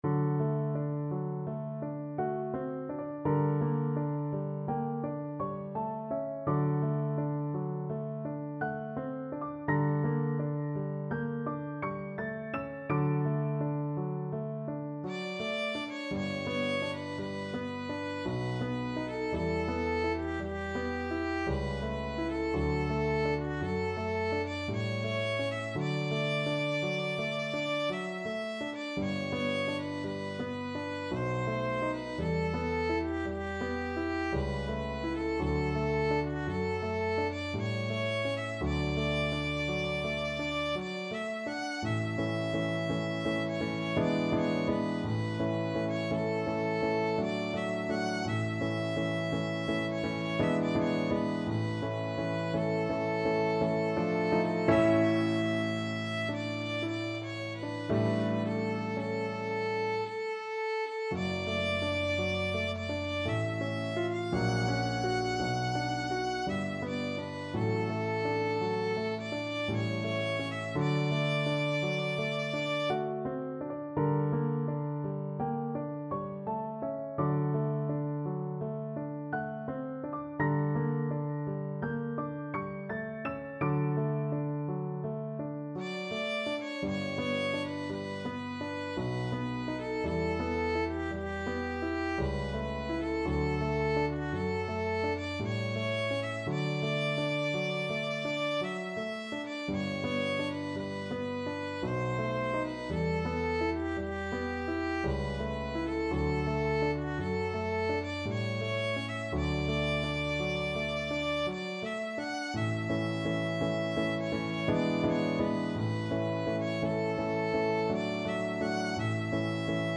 Violin version
3/4 (View more 3/4 Music)
~ = 56 Ziemlich langsam
Classical (View more Classical Violin Music)